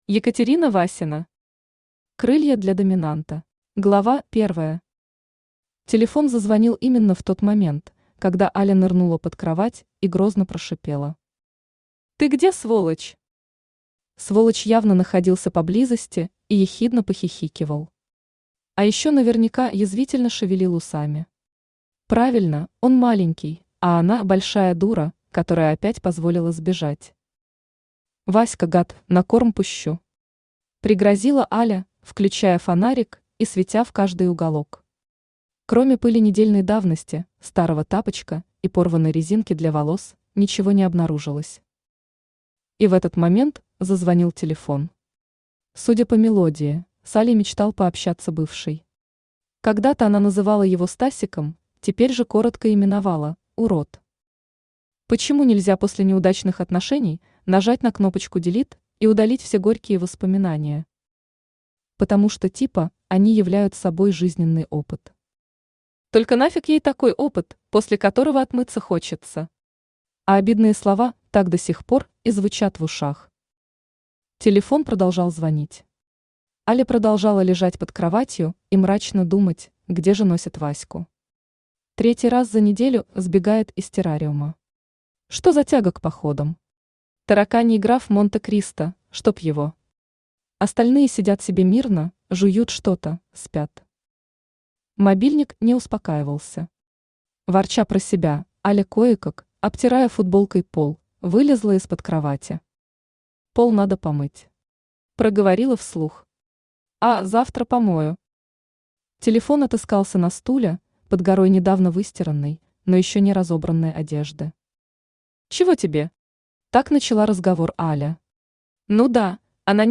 Аудиокнига Крылья для Доминанта | Библиотека аудиокниг
Aудиокнига Крылья для Доминанта Автор Екатерина Васина Читает аудиокнигу Авточтец ЛитРес.